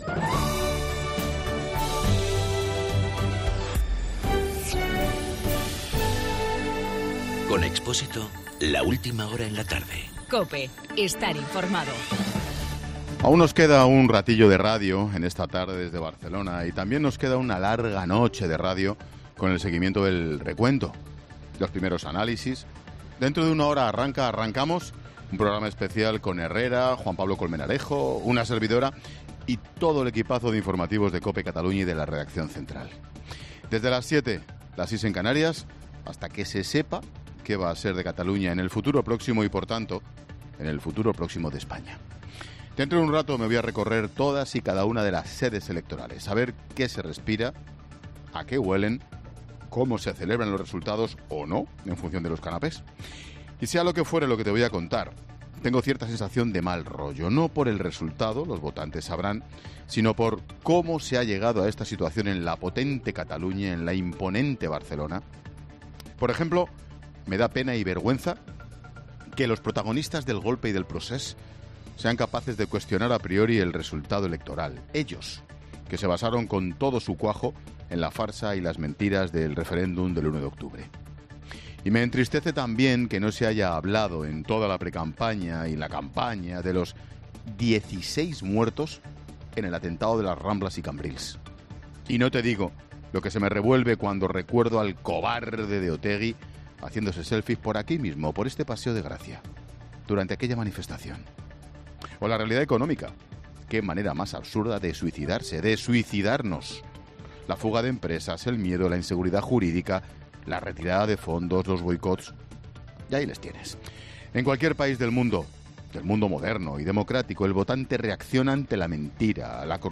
Monólogo de Expósito
El comentario de Ángel Expósito desde Barcelona por las elecciones en Cataluña.